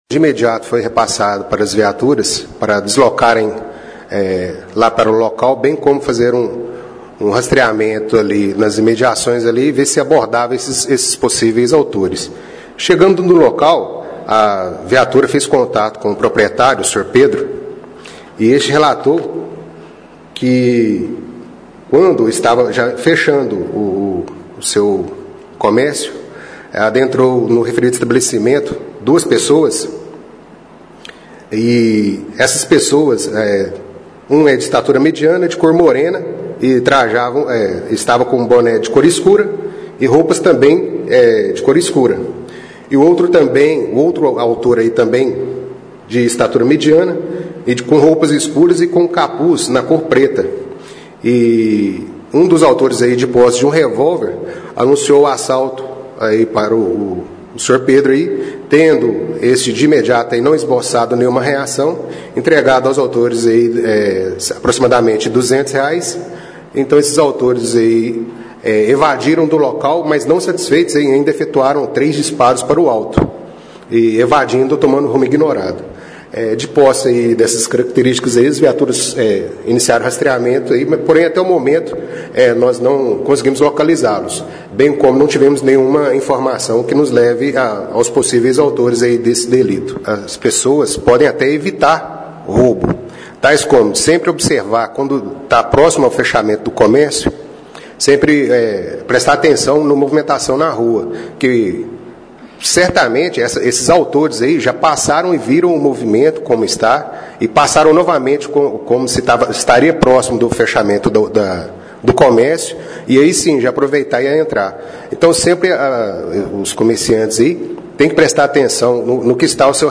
(Clique no player e ouça a entrevista). O local foi assaltado por duas pessoas com roupas escuras que fizeram ameaças com uma arma e, depois de apanhar o dinheiro, fizeram três disparos para o alto.